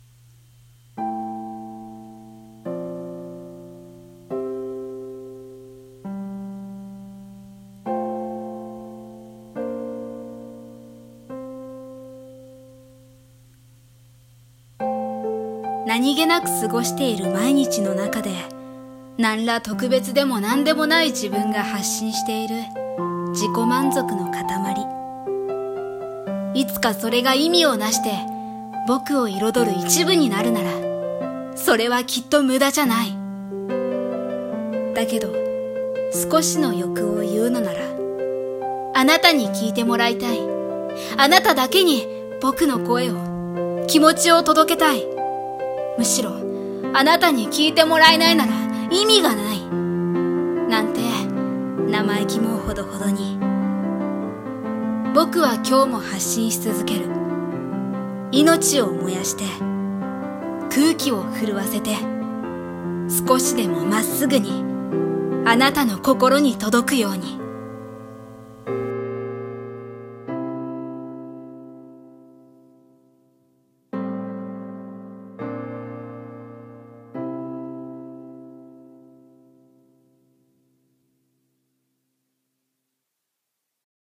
【声劇】あなたに聞いてほしい